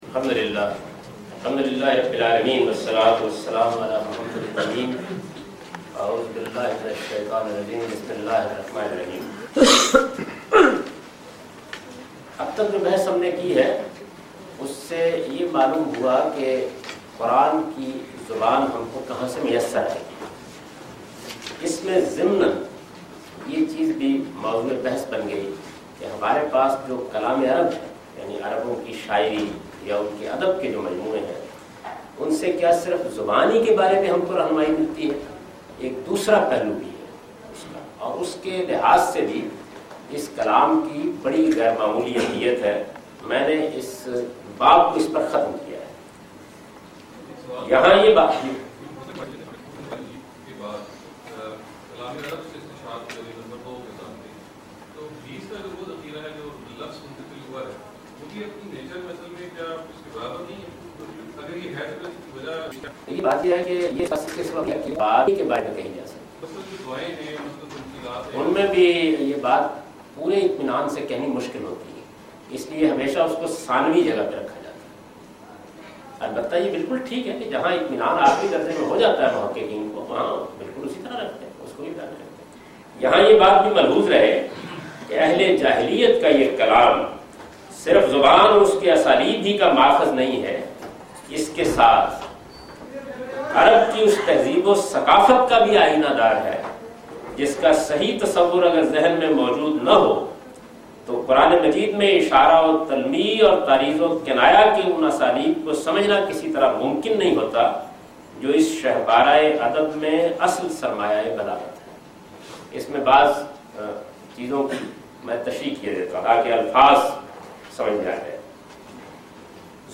A comprehensive course on Islam, wherein Javed Ahmad Ghamidi teaches his book ‘Meezan’.
In this lecture he teaches the importance of appreciation of classical Arabic in order to truly understand Quran.